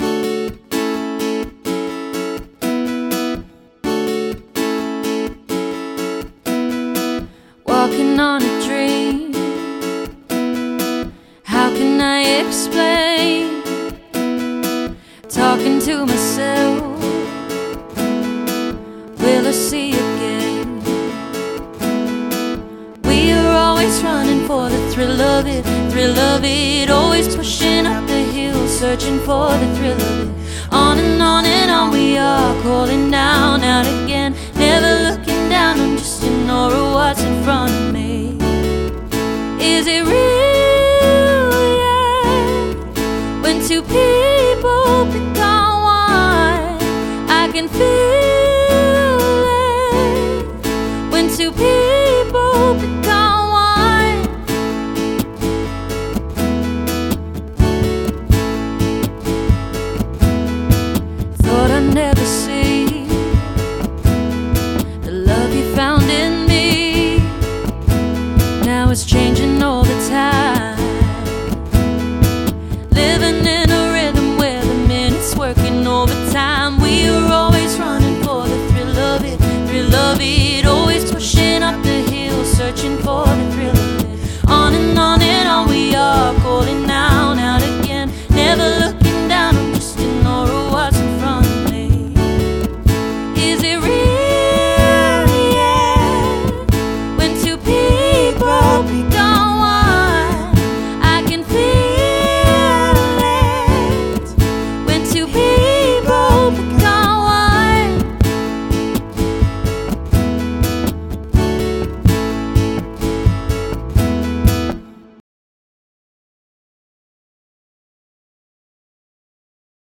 Dual Vocals | Guitar | Piano | Looping | DJ | MC
an exciting and dynamic Melbourne based acoustic duo
current and classic cover songs
strong & smooth lead vocals
energetic guitar playing, harmonies & looping skills